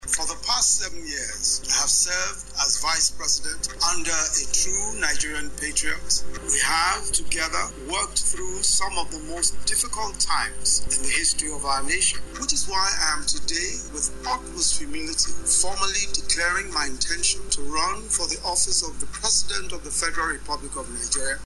Nigeria’s Vice-President Yemi Osinbajo has officially declared his intention to contest the 2023 presidential election. Osinbajo, who declared his intention in a short broadcast shared on his Twitter handle.